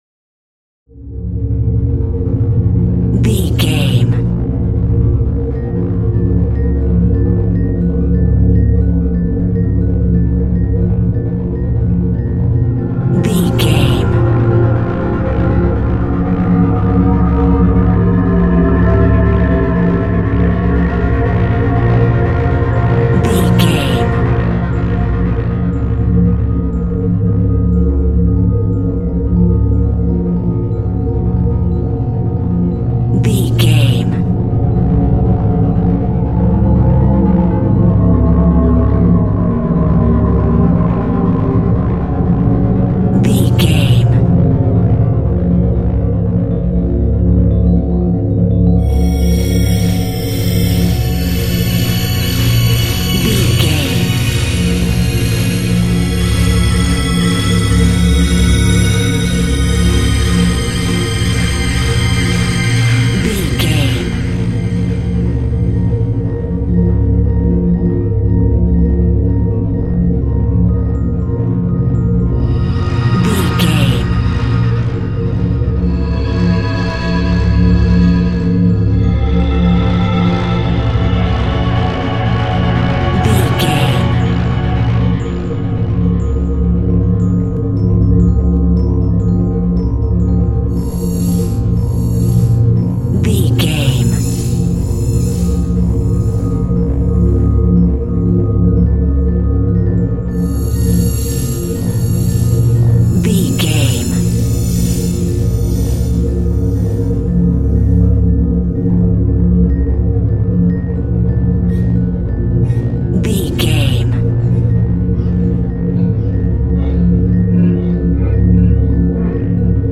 Thriller
Aeolian/Minor
B♭
ominous
dark
haunting
eerie
synthesiser
electric piano
ambience
pads